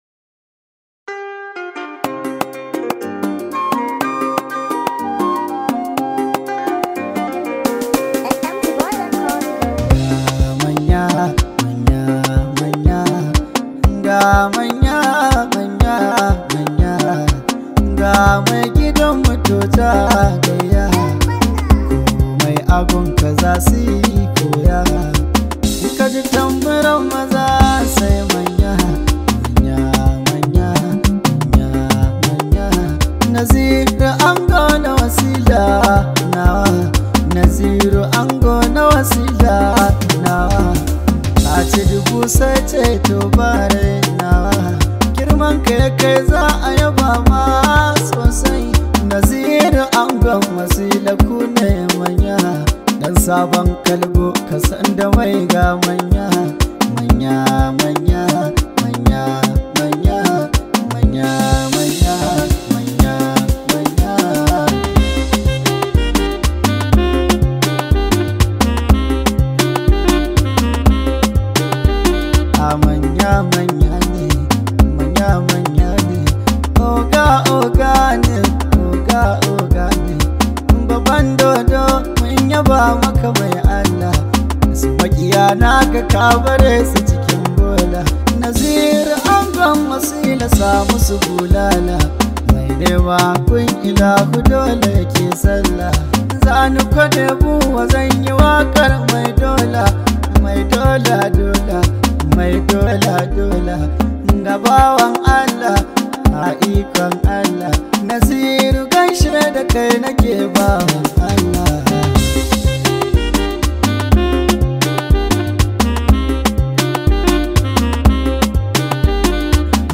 Hausa Songs
high vibe hausa song